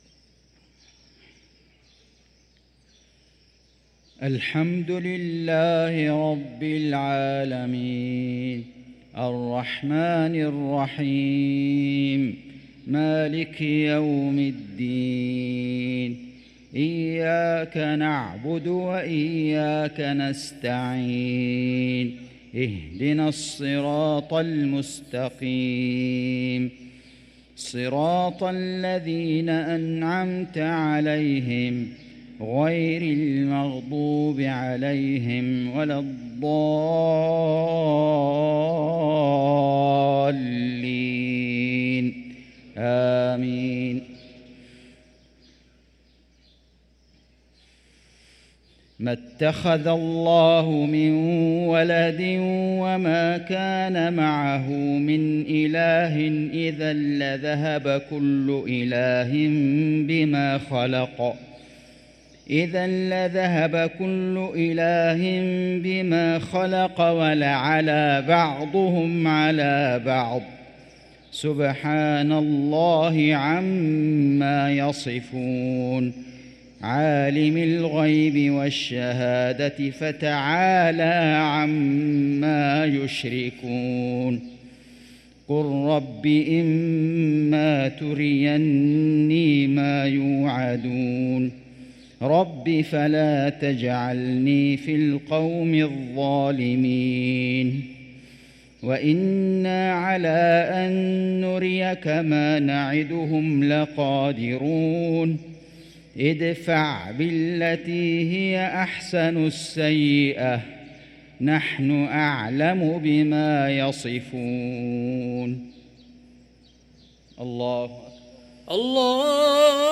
صلاة المغرب للقارئ فيصل غزاوي 20 جمادي الآخر 1445 هـ
تِلَاوَات الْحَرَمَيْن .